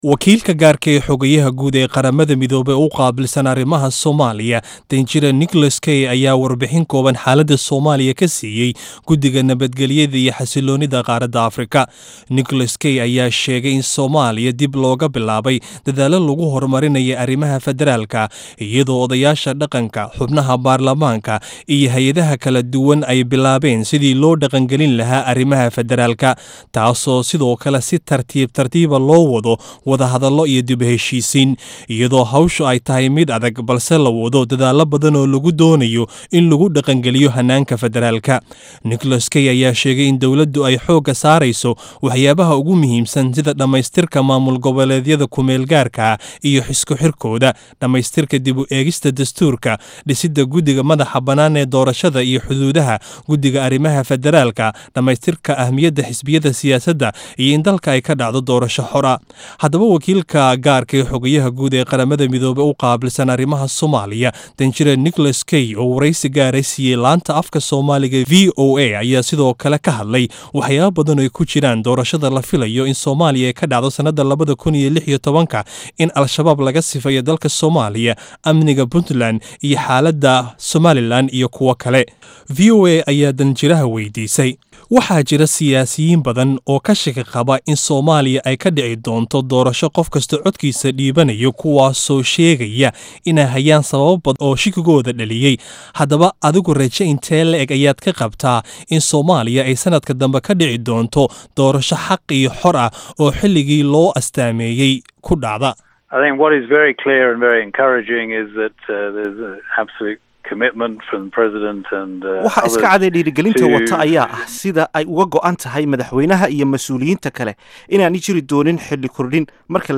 Warysi uu siiyey Idaacada VOA-da aya wuxuu uga hadlay Arimahaasi, Sidoo kalana waxaa la waydiiyey Hadalkii dhowaan kasoo yeeray Madaxweynaha Somaliland Axmed Siilaanyo ee ku aadanaa in hadii la dhiso ciidan Soomaaliyeed uu dhibaato keenayo“Dhamaan dalalka madaxa banaan waxya u baahan yihiin inay dhistaan Ciidan Qaran, Soomaaliya kama Duwana dalalka kale ee u baahan Ciidan difaaca Qarankooda”